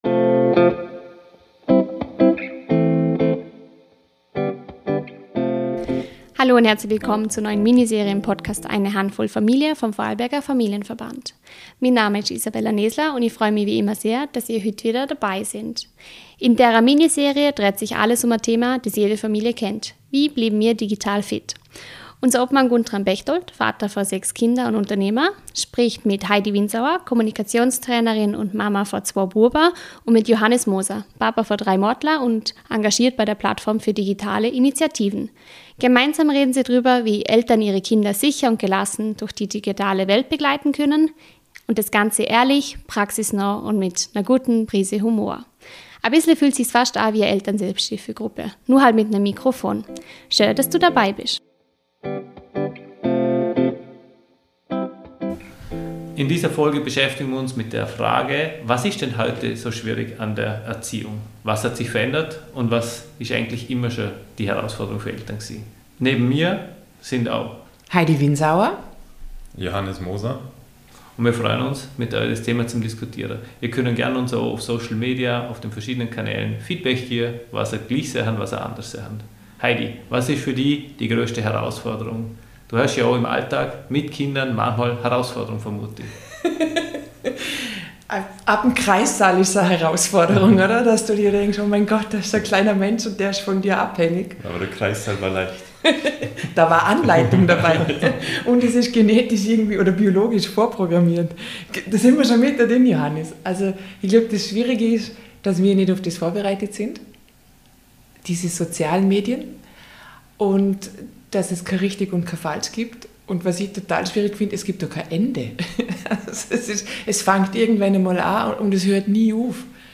Im Gespräch geht es um Vorbilder, um den Umgang mit Bildschirmzeit, um Regeln im Familienalltag und um die Bedeutung von Gesprächen auf Augenhöhe.